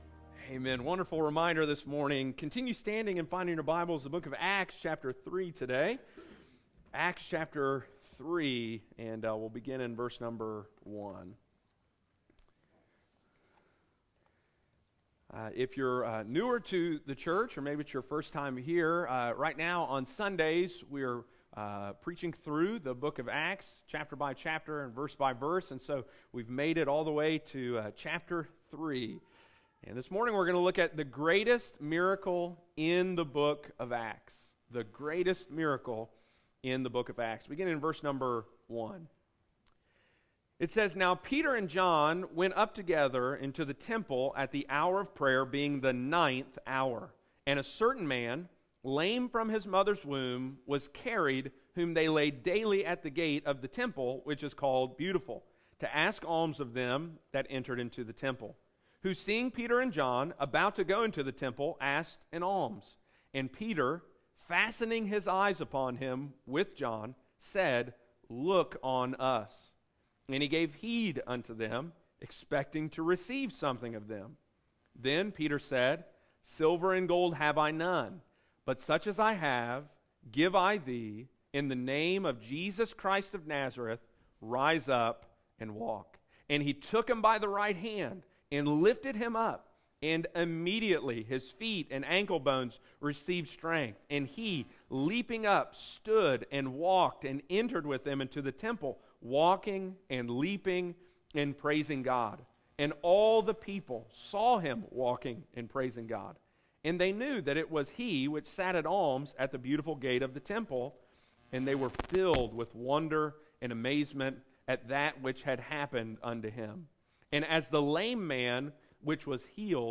Sunday morning, February 13, 2022.